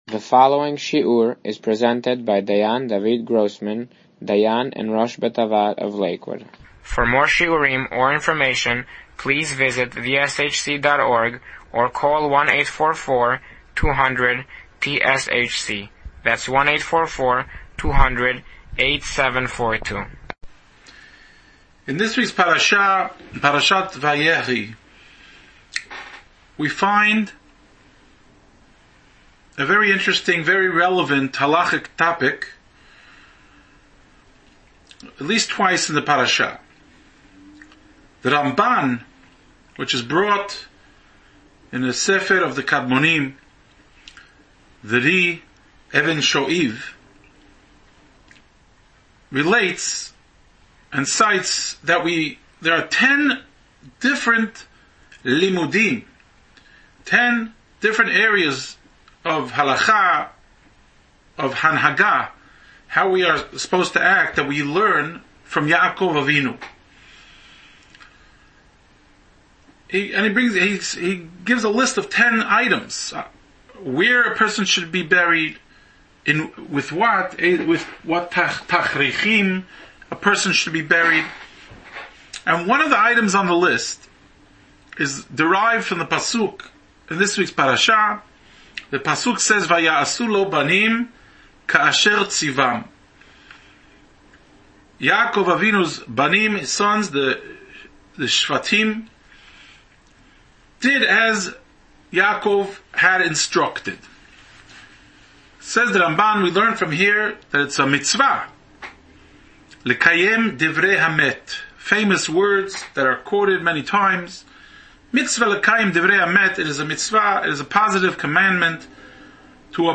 Summary Parasha & Halacha Shiur for Parashat VaYehi